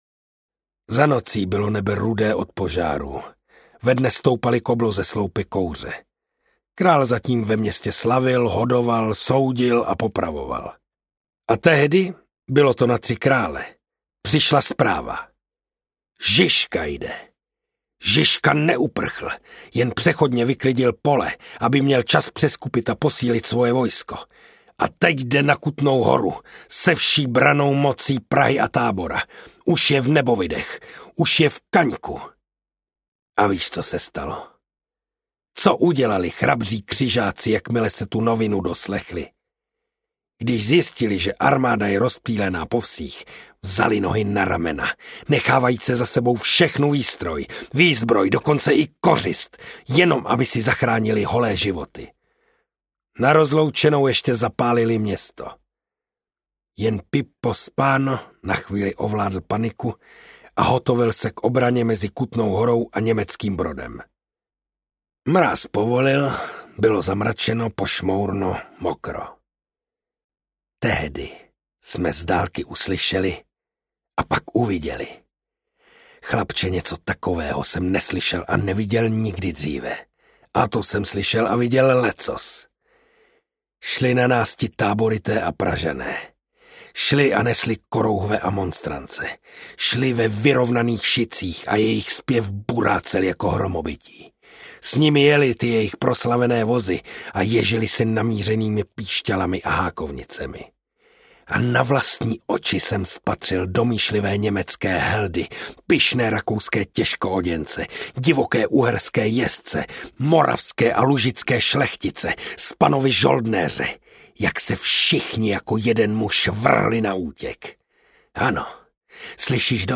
Narrenturm audiokniha
Ukázka z knihy